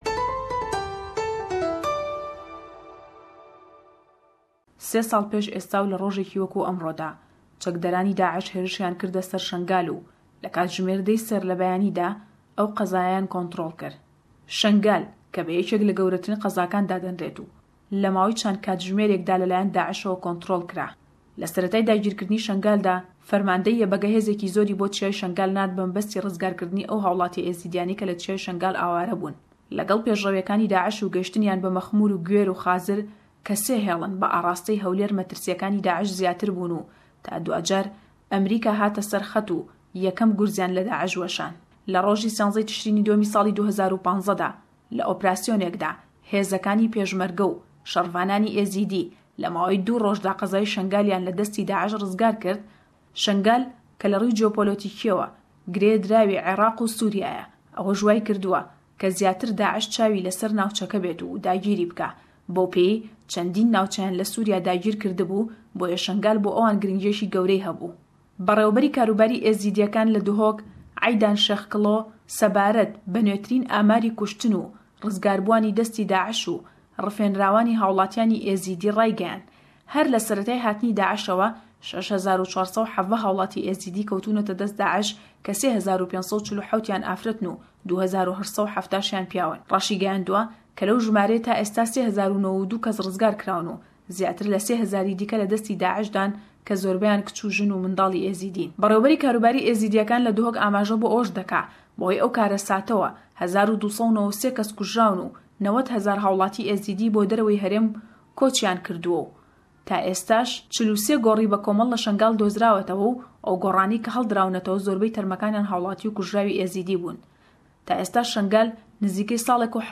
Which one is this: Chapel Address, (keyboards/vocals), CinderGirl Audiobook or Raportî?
Raportî